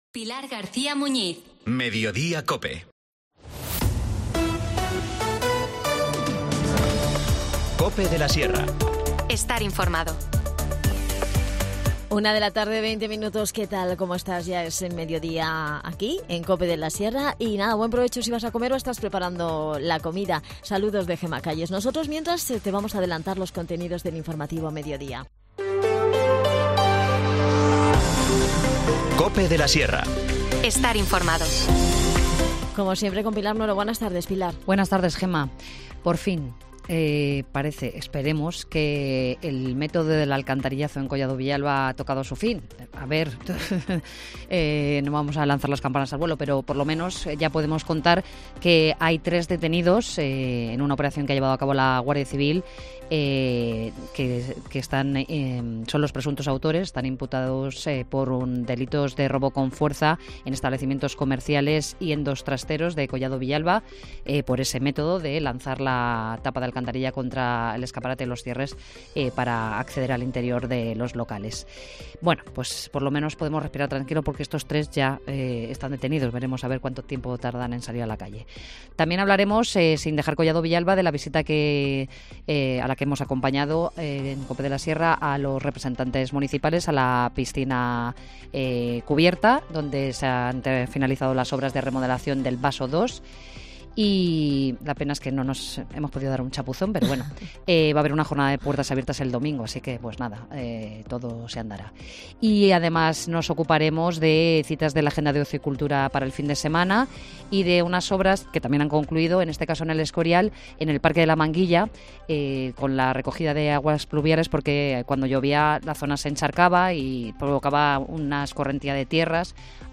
13:20 | Magazín| Mediodía COPE de la Sierra 16 de febrero de 2024
Las desconexiones locales son espacios de 10 minutos de duración que se emiten en COPE, de lunes a viernes.